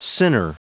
Prononciation du mot sinner en anglais (fichier audio)
Prononciation du mot : sinner